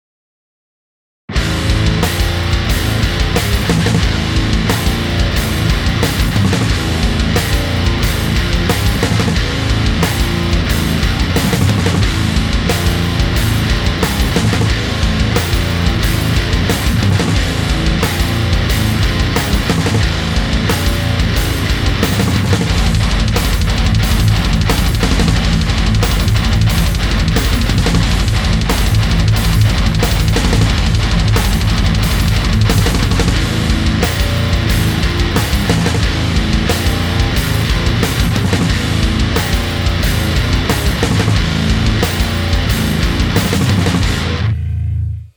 Вот и я свой скромный пример мятола выложу.. Записывался на отстойном шлаке дома, в общем, не запись, а танцы с бубнами...
- Мой самопальный ламповый усилитель с преампом по схеме Krank Krankenstain, 2хЕСС83 tesla + оконечник на 6п14п SE 5 вт.
- Кабинет 2х8' Celestion Super 8, мощностью 2х15вт = 30 вт.
- Микрофон ATTR
Записывался на громкости, примерно громкого крика. В комнате было громко, но окна не дрожали. Потц громкости был выкручен примерно на 9 часов.
Микрофон находился в позиции CapEdge OffAxis